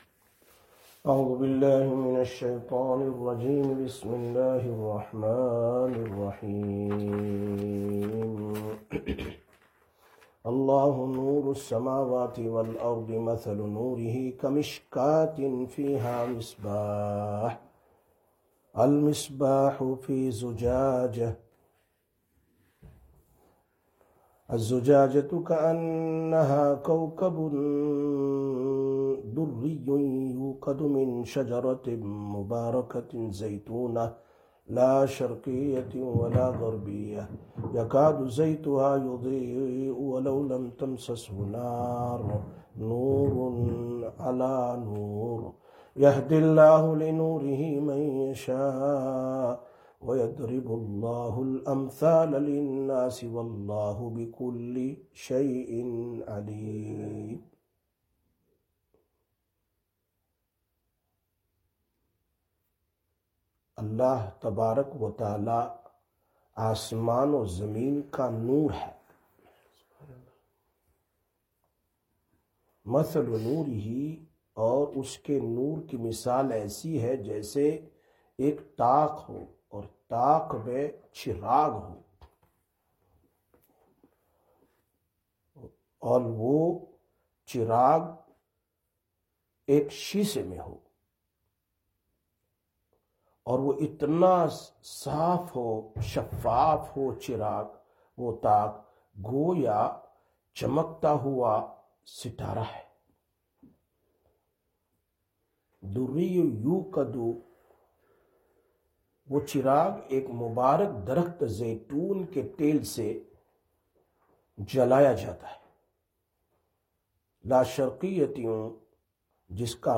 Surah An-Nur, Bayans, Bayans 2025, Quran Tafseer Audio